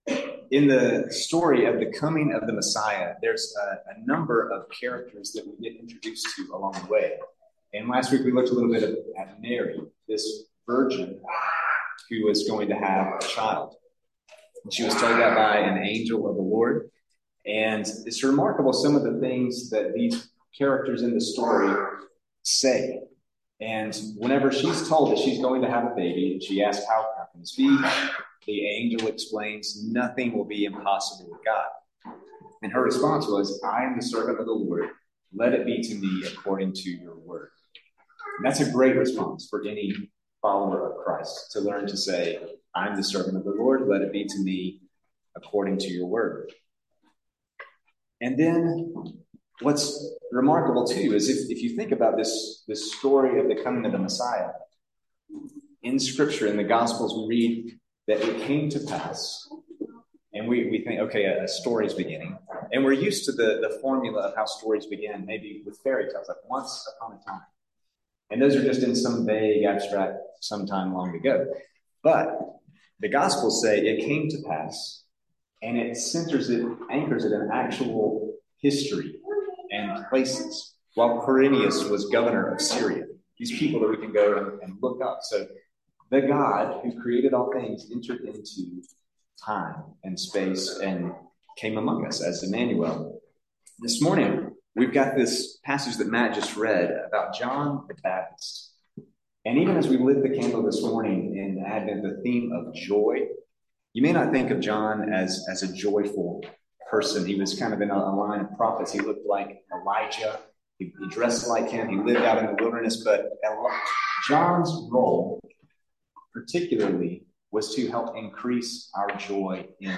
Sermons | Christ the King